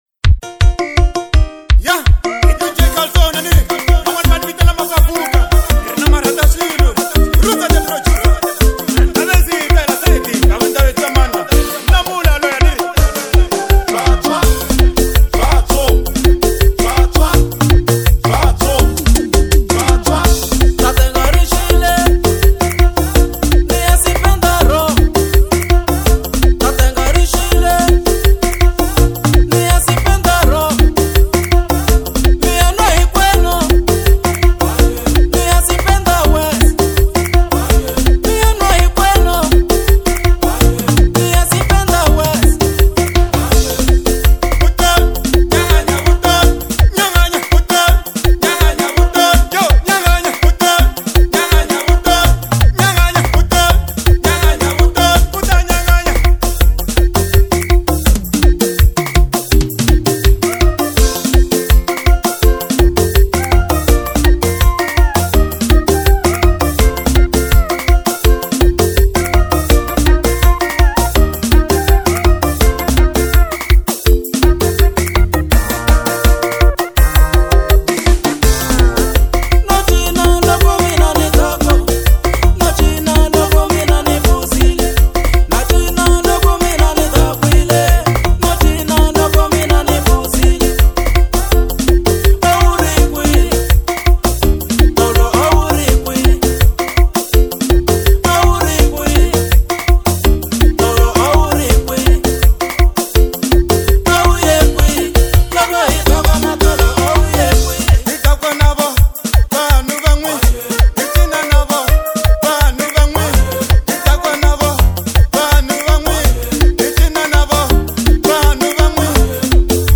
Genre : Xitsonga